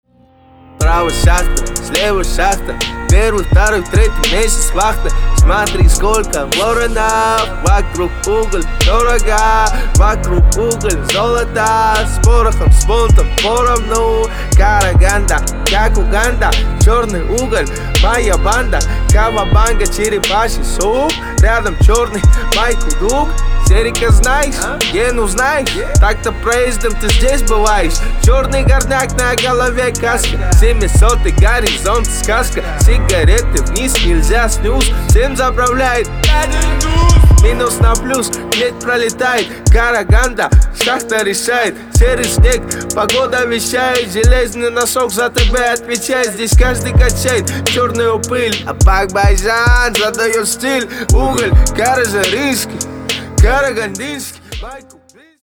Hip-hop / Rap